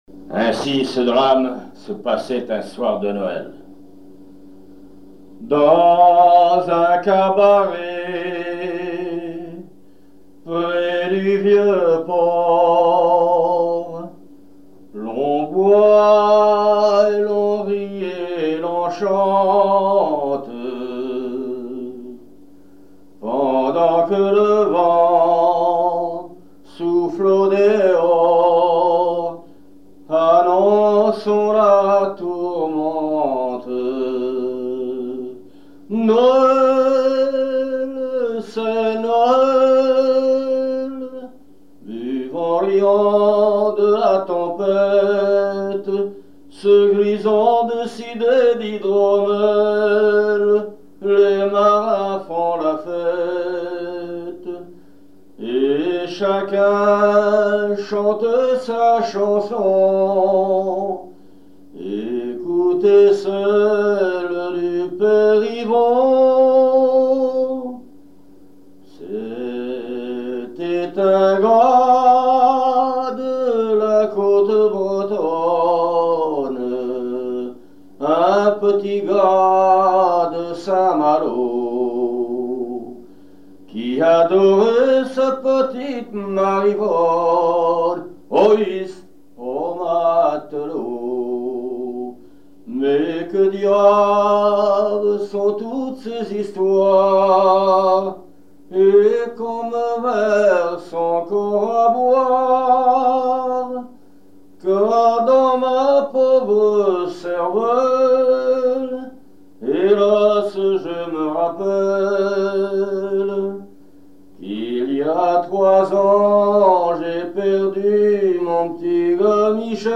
Genre strophique
répertoire de chansons
Pièce musicale inédite